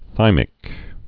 (thīmĭk)